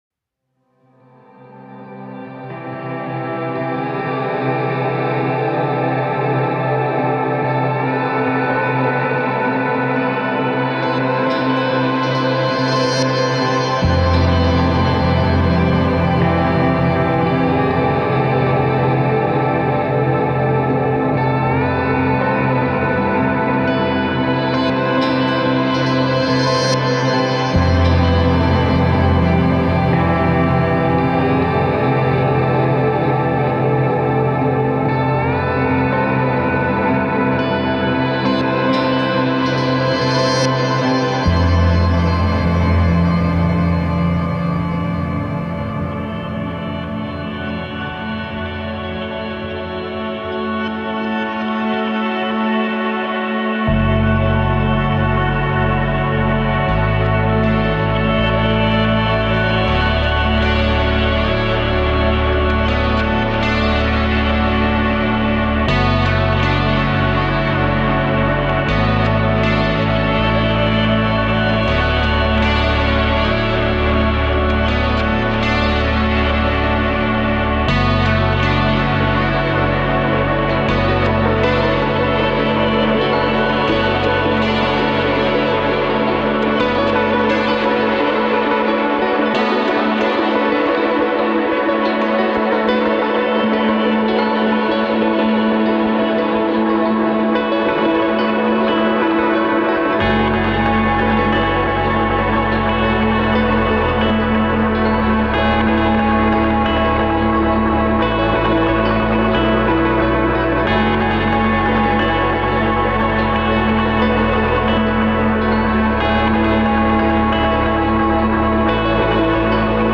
70 – 90 BPM